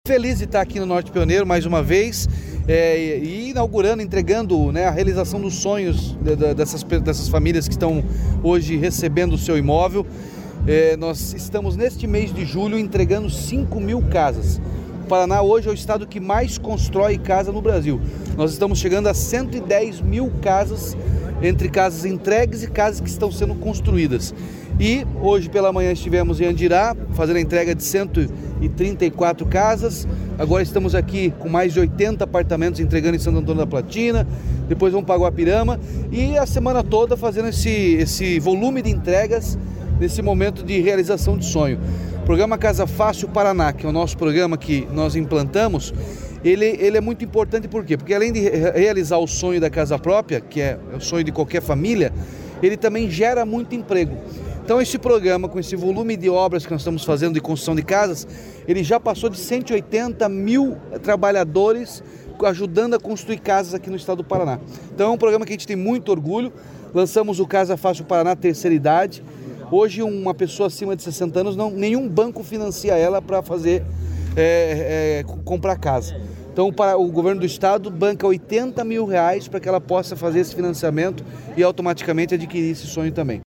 Sonora do governador Ratinho Junior sobre a entrega de apartamentos em Santo Antônio da Platina